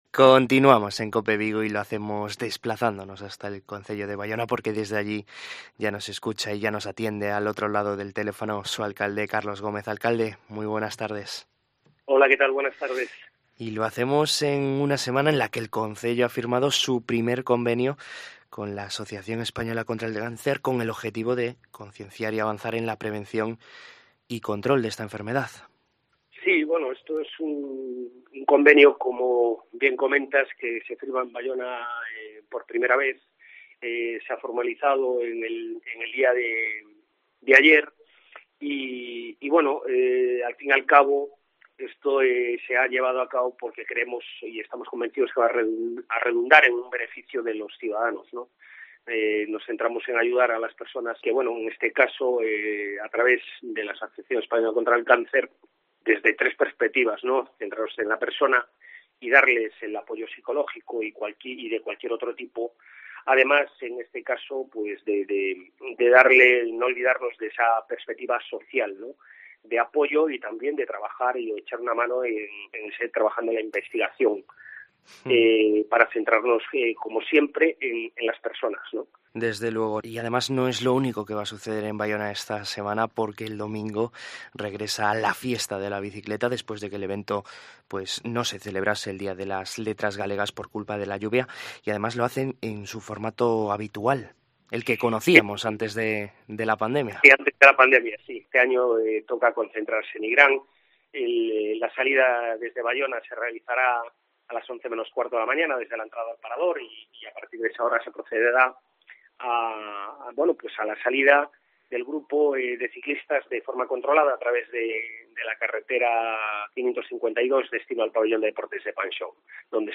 En COPE Vigo hablamos con el alcalde de Baiona, Carlos Gómez, para conocer la actualidad de este municipio del sur de la provincia de Pontevedra